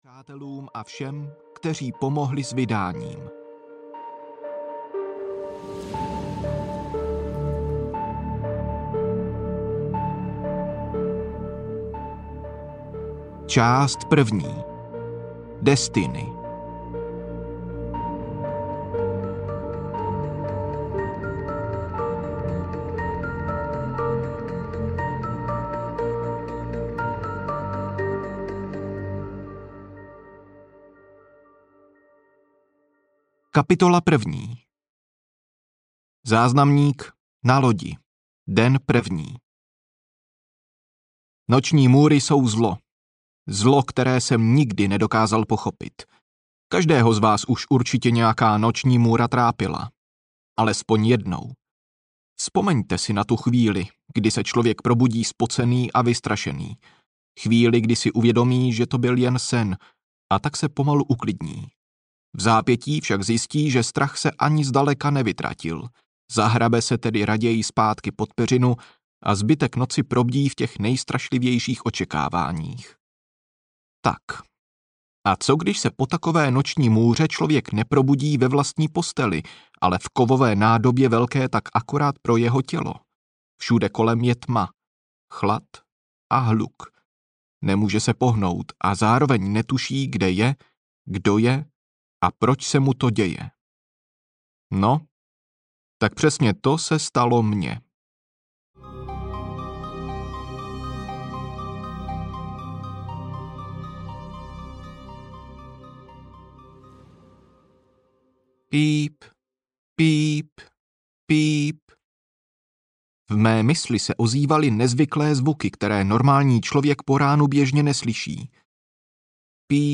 V prázdnotě audiokniha
Ukázka z knihy